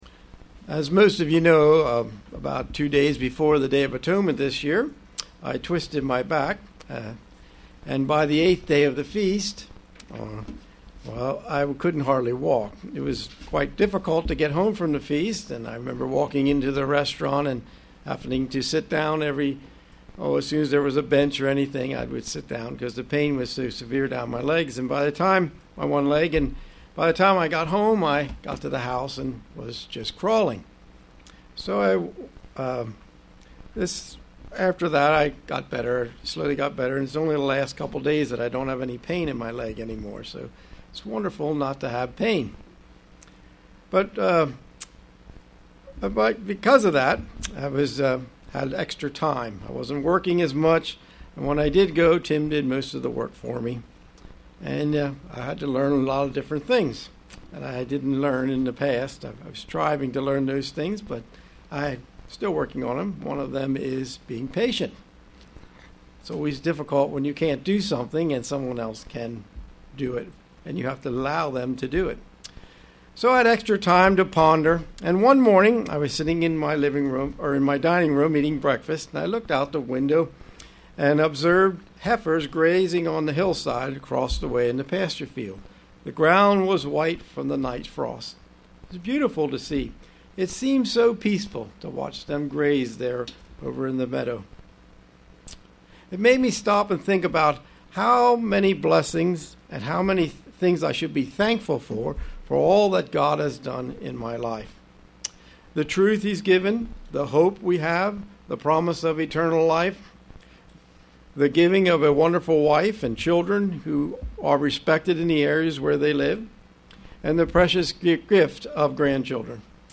Sermons
Given in York, PA